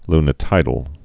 (lnĭ-tīdl)